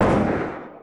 m4a1fire_dist.wav